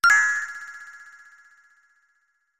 Sound effect from Super Mario RPG: Legend of the Seven Stars
Self-recorded using the debug menu
SMRPG_SFX_Frog_Coin.mp3